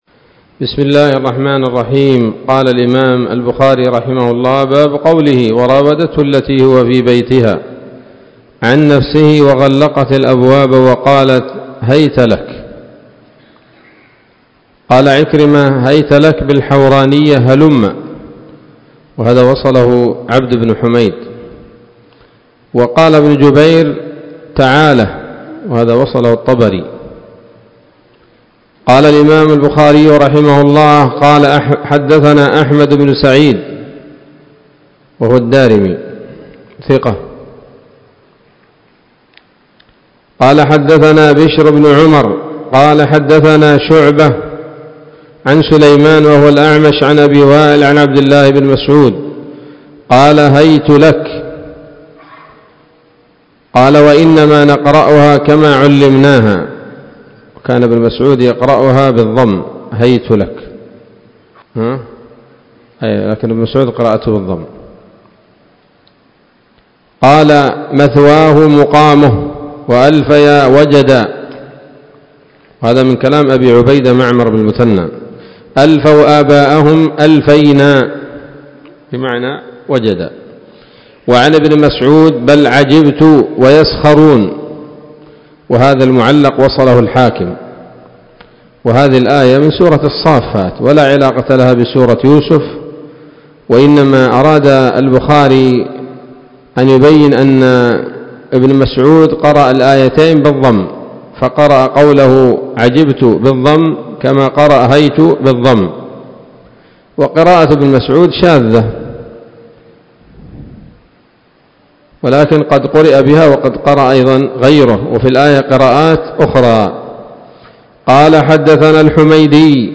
الدرس الحادي والأربعون بعد المائة من كتاب التفسير من صحيح الإمام البخاري